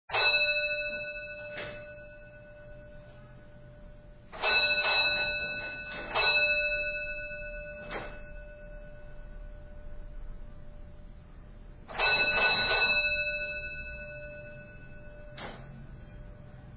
II. Bells
bell3.mp3